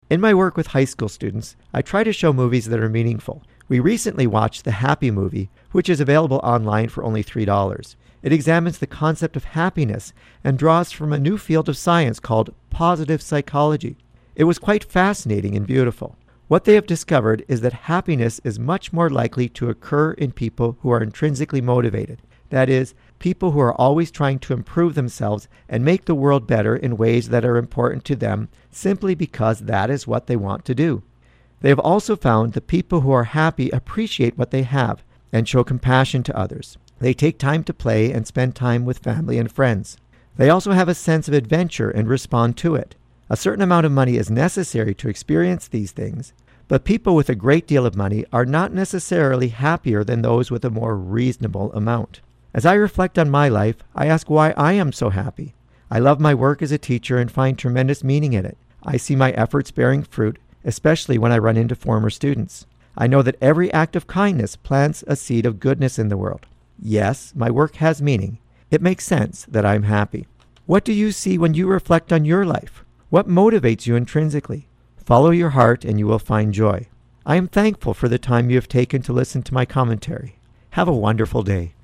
Recording Location: CFIS-FM, Prince George
Type: Commentary
224kbps Stereo